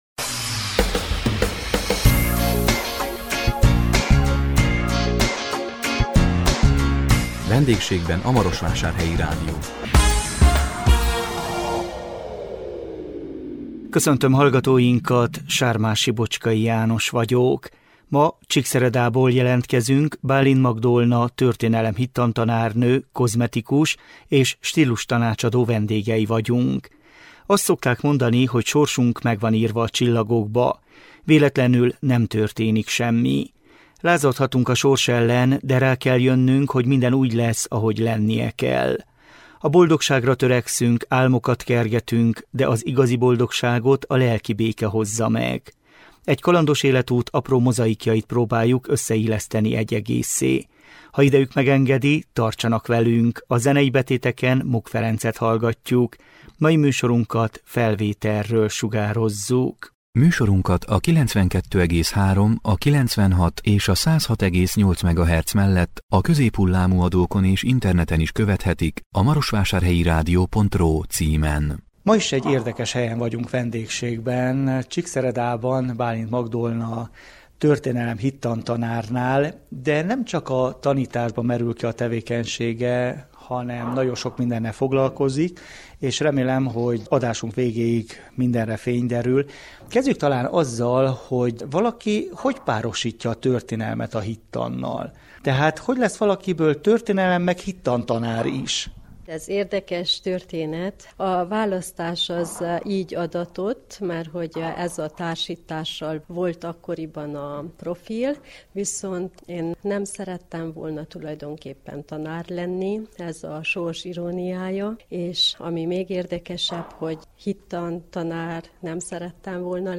A 2025 szeptember 4-én közvetített VENDÉGSÉGBEN A MAROSVÁSÁRHELYI RÁDIÓ című műsorunkkal Csíkszeredából jelentkeztünk